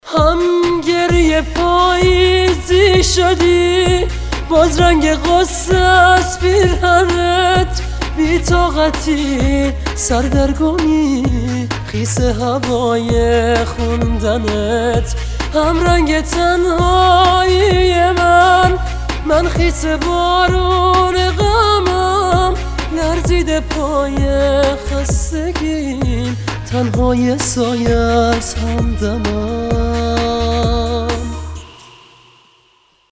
زنگ موبایل با کلام و رمانتیک